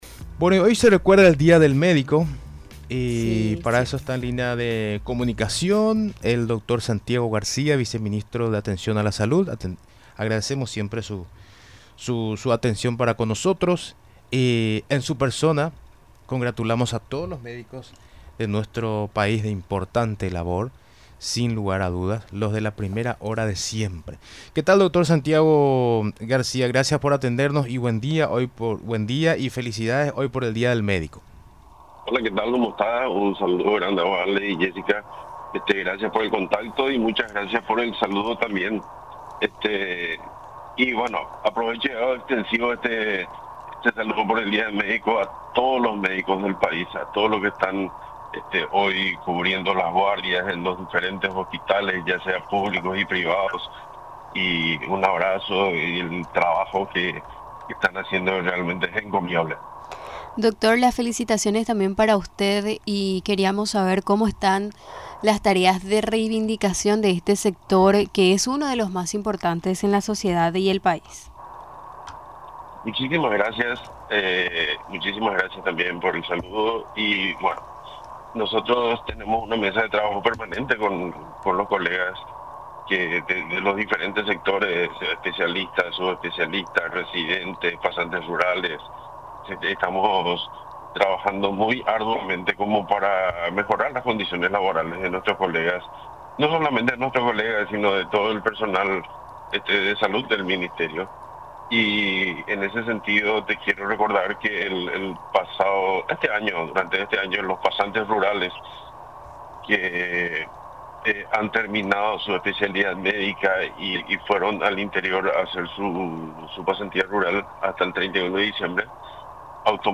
Sobre el punto, el viceministro de Atención a la Salud, doctor Santiago García, se refirió en la tarea que realizan en el día a día.